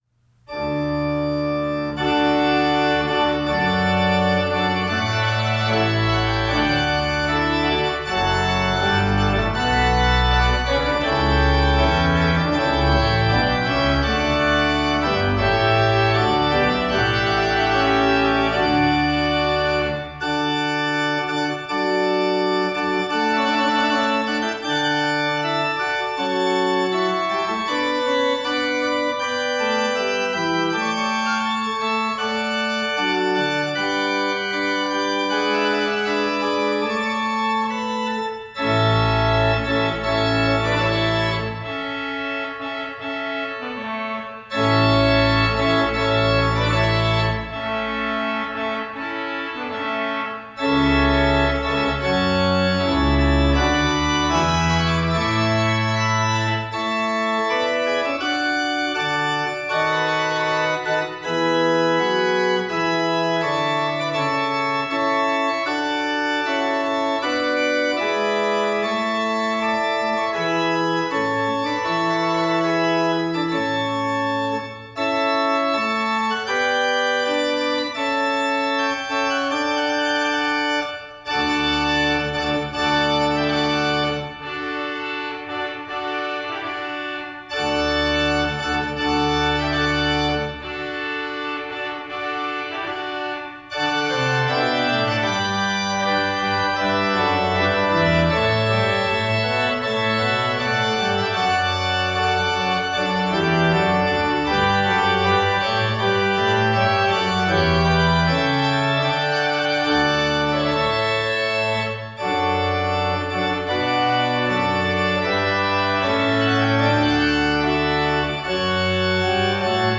We’ve prepared this page to help you choose organ music for your wedding ceremony.
A. Grand & Glorious